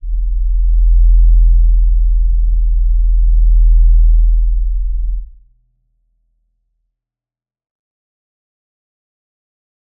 G_Crystal-E1-mf.wav